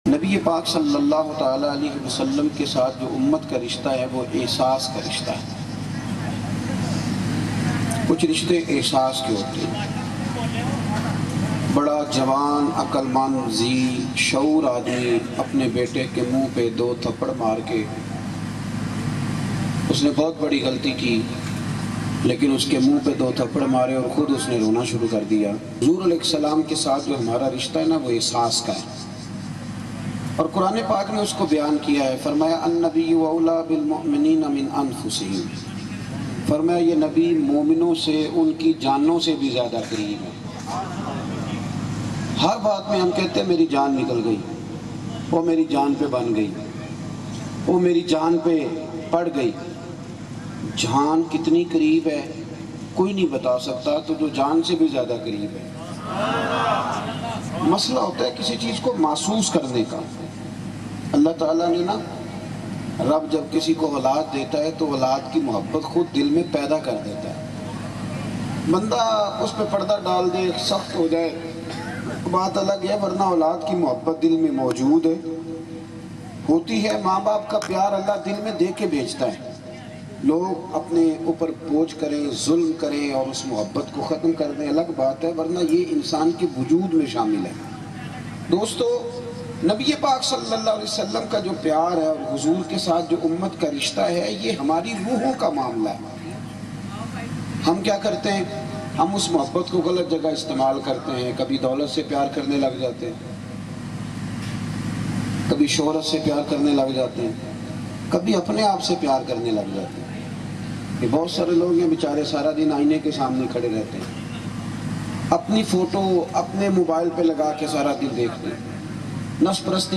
Bayan